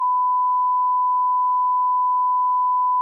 bleep.mp3